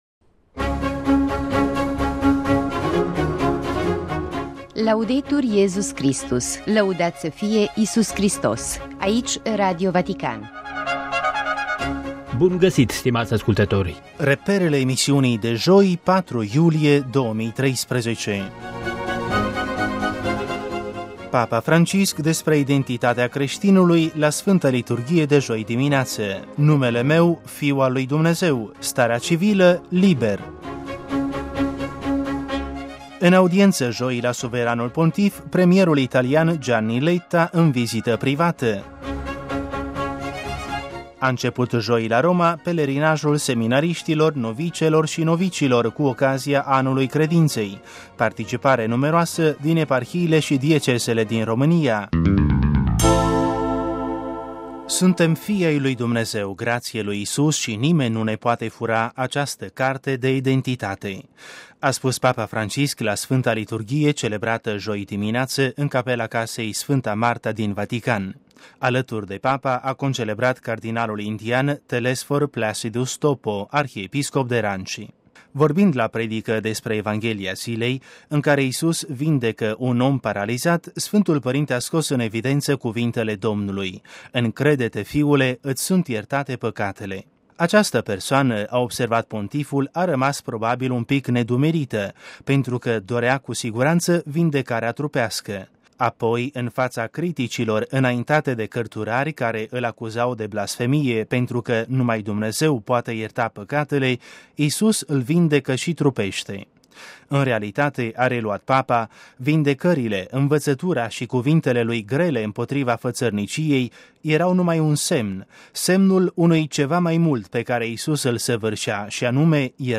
- În audienţă, joi, la Suveranul Pontif, premierul italian Gianni Letta, în vizită privată - A început joi la Roma pelerinajul seminariştilor, novicelor şi novicilor, cu ocazia Anului Credinţei; participare numeroasă din eparhiile şi diecezele din România - Interviu cu pr.